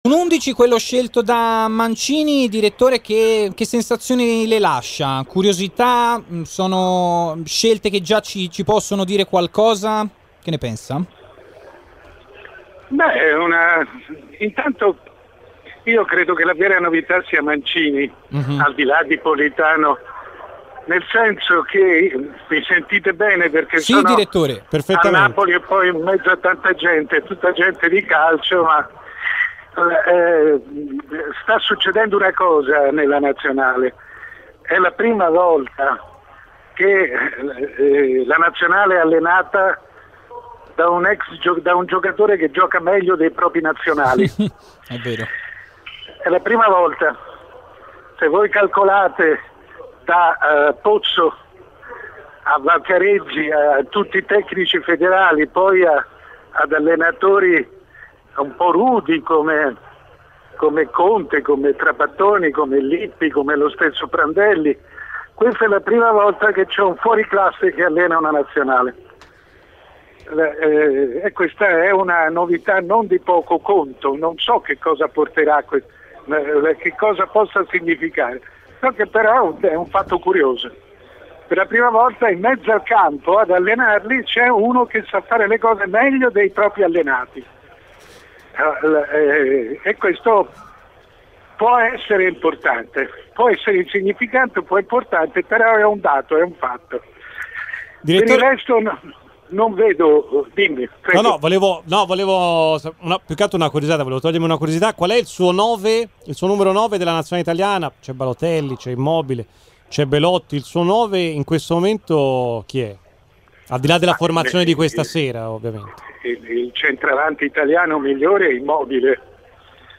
Mario Sconcerti, prima firma del giornalismo sportivo italiano, nel collegamento con il Live Show di RMC Sport ha analizzato i temi di Italia-Arabia Saudita, la prima uscita di Roberto Mancini come ct azzurro: